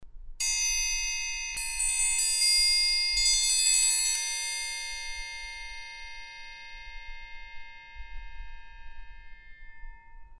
The Black Swamp 10 Steel Artisan Triangle sound rich and dynamic. Each size triangle produces a shimmering and consistent overtone spectrum throughout its entire dynamic range.
The Black Swamp AT10 10 Artisan Triangle produces a huge sound for when a triangle really needs to be heard!
AT10 Triangle Sound Sample Listen to the 10" Artisan Steel Triangle (AT10) performed with a Teardrop Spectrum beater (SPEC4) Headphones recommended!